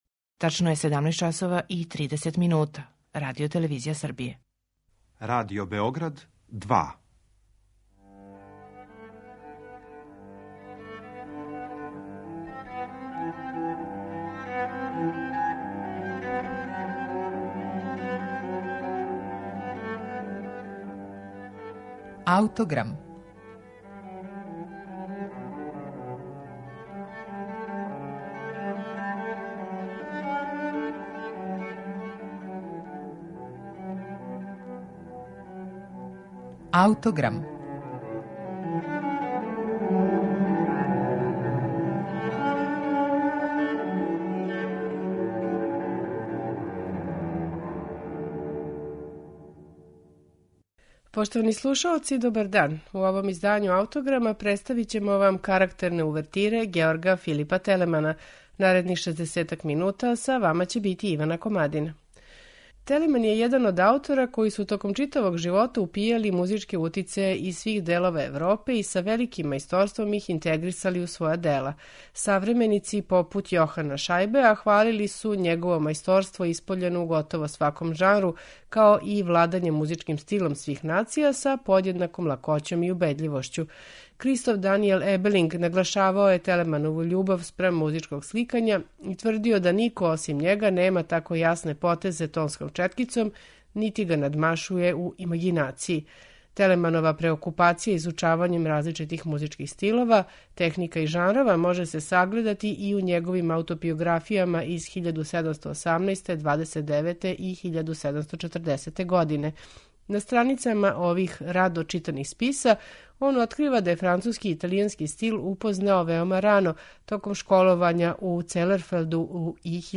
Телеманов космополитски музички стил у данашњем Аутограму представићемо уз две карактерне увертире, које изводи ансамбл Arte dei Suonatori